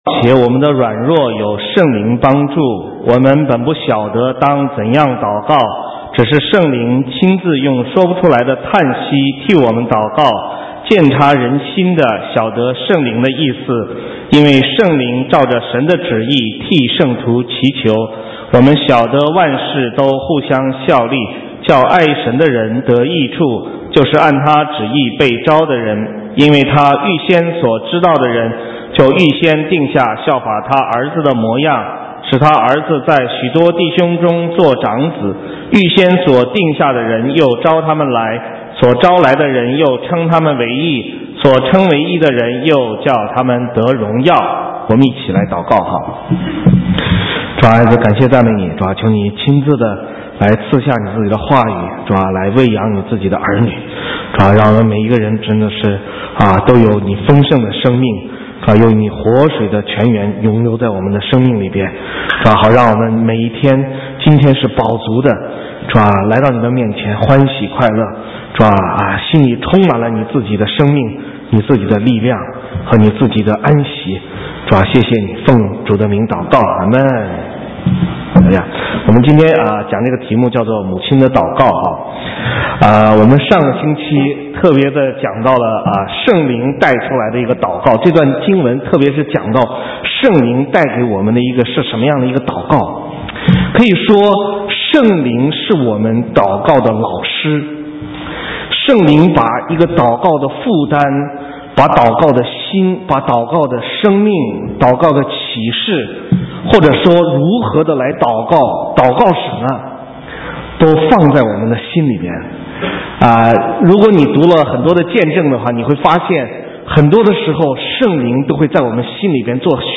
神州宣教--讲道录音 浏览：母亲的祷告 (2012-05-13)